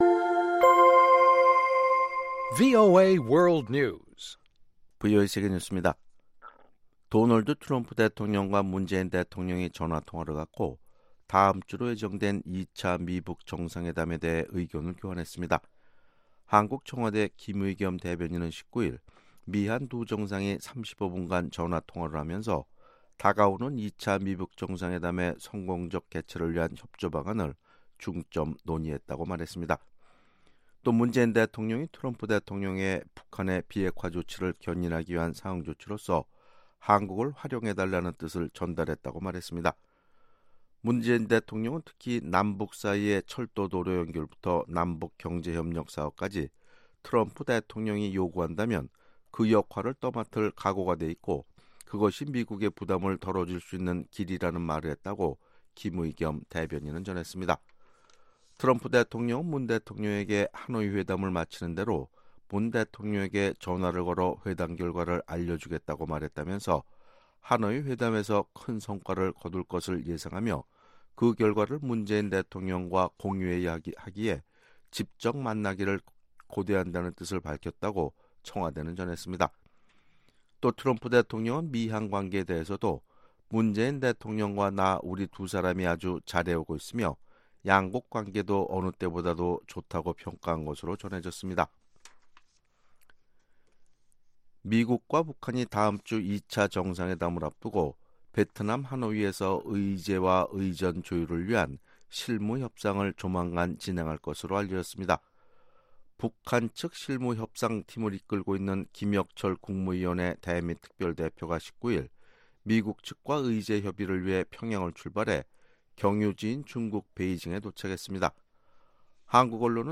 VOA 한국어 아침 뉴스 프로그램 '워싱턴 뉴스 광장' 2019년 2월 20일 방송입니다. 트럼프 대통령이 북한에 대한 재정지원은 일절 금지하고 인권증진 활동을 위해서만 지출하도록 하는 예산안에 서명했습니다. 미국의 주요 언론들은 트럼프 행정부가 비핵화와 상응조치와 관련한 단계별 ‘로드맵’ 마련에 주력하고 있다고 보도하면서도 중대한 합의 도출은 여전히 미지수라고 지적했습니다.